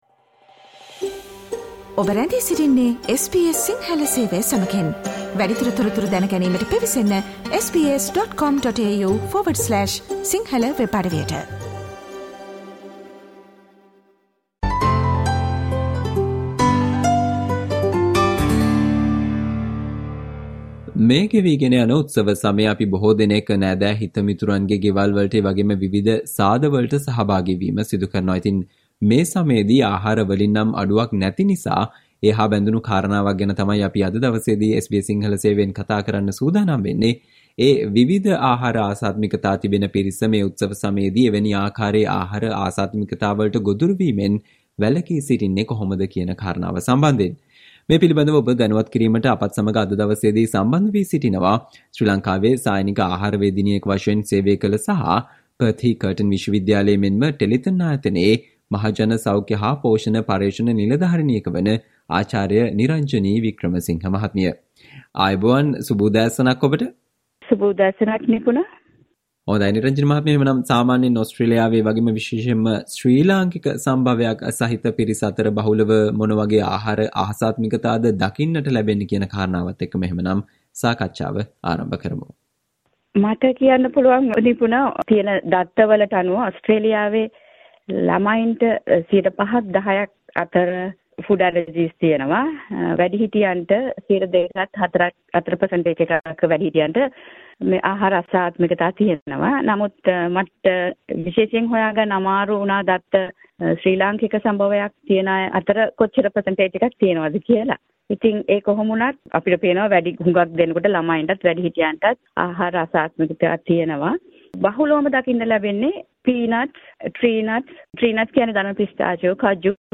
විවිධ ආහාර අසාත්මිකතා තිබෙන පිරිස, මේ උත්සව සමයේදී, එවැනි ආහාර අසාත්මිකතා වලට ගොදුරු වීමෙන් වැළකී සිටීමට අනුගමනය කල යුතු දේ සම්බන්ධයෙන් SBS සිංහල සේවය සිදු කල සාකච්චාවට සවන්දෙන්න